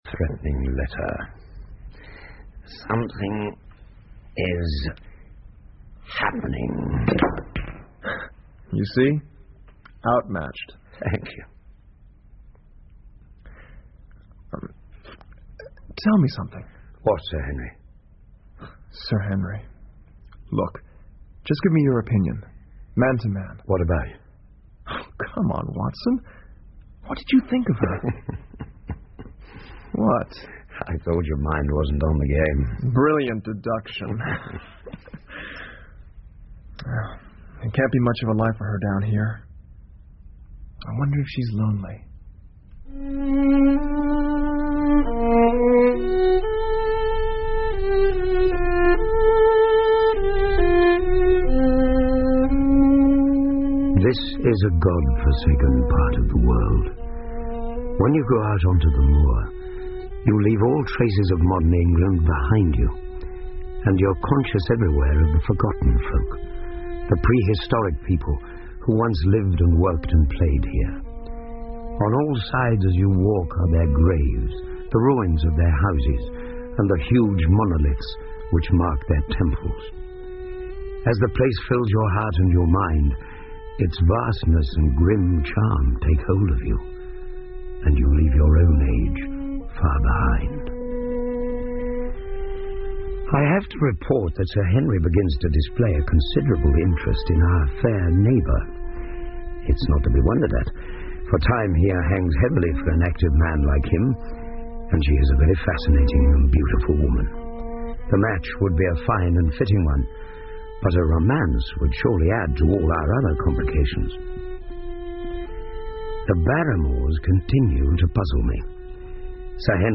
福尔摩斯广播剧 The Hound Of The Baskervilles - Part 01-11 听力文件下载—在线英语听力室